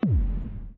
hyperloop_crowbar.ogg